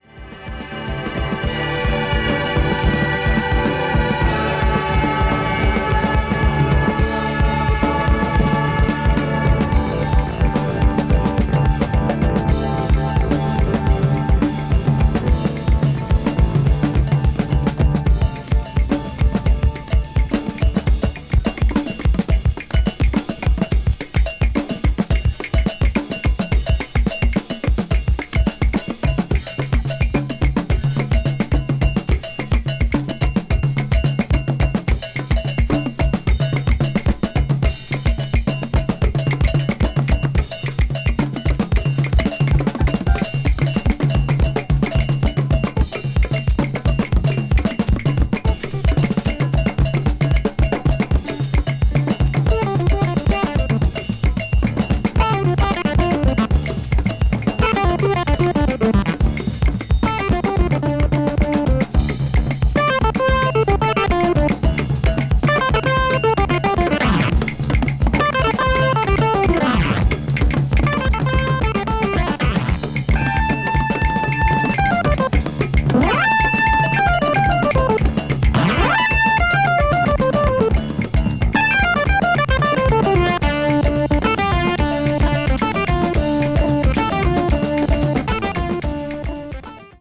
A Kraut-jazz-rock group